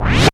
SLIDE UP.wav